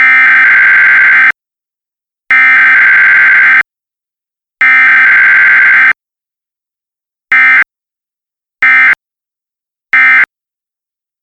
All stations transmit a 1050 Hz tone immediately before issuing a watch or warning, and this serves to activate the alert feature on many older radios.
SAME data is transmitted as an AFSK Data Burst, with each individual bit lasting 1920 µs (1.92 ms) each, giving a bit rate of 5205/6 bits per second.